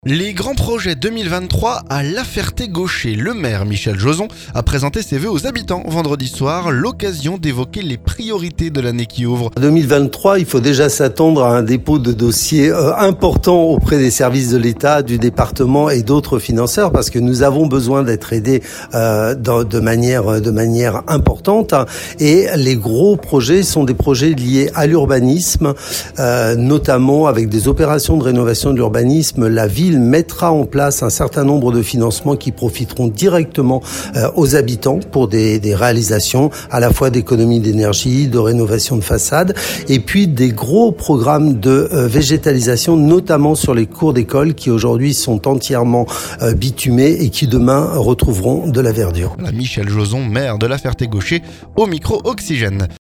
Les grands projets 2023 à La-Ferté-Gaucher. Le maire, Michel Jozon, a présenté ses vœux aux habitants vendredi soir. L'occasion d'évoquer les priorités de l'année qui ouvre.